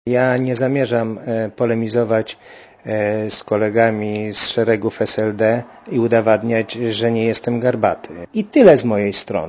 W Płocku z niektórymi działaczami SLD rozmawiał reporter Radia Zet.